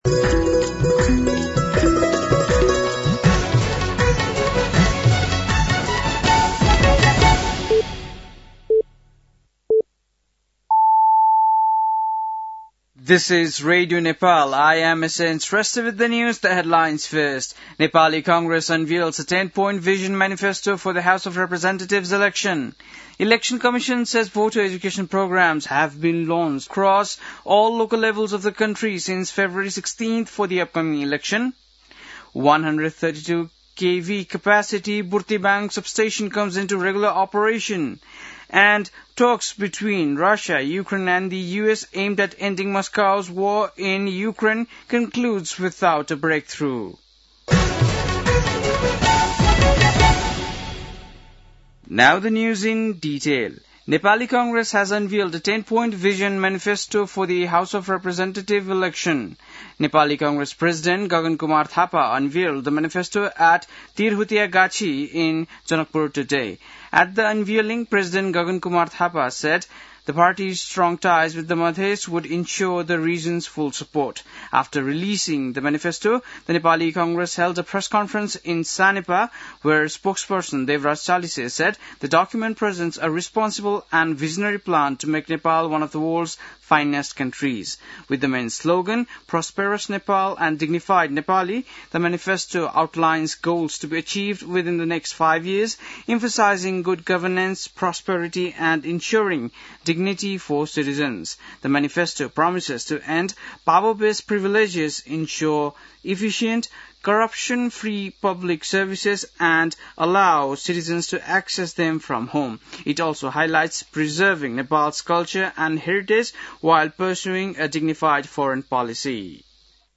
बेलुकी ८ बजेको अङ्ग्रेजी समाचार : ६ फागुन , २०८२